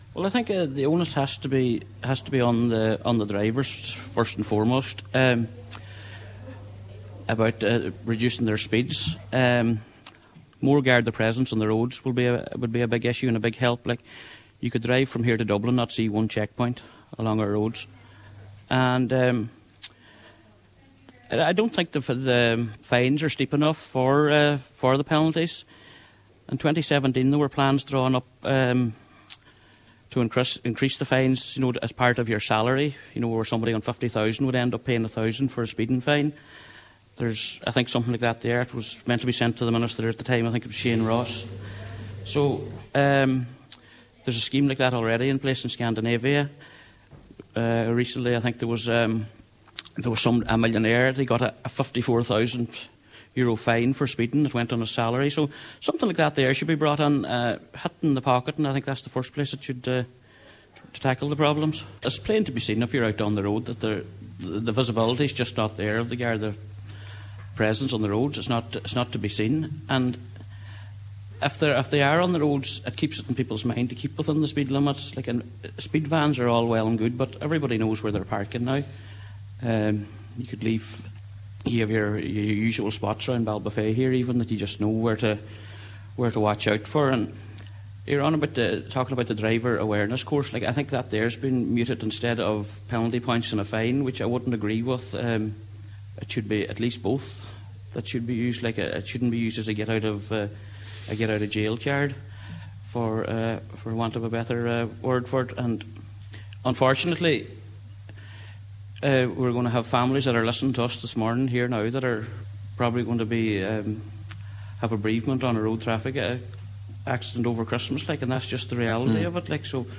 Councillor Scanlon on today’s Nine til Noon Show, appealed to motorists to be responsible over the festive period: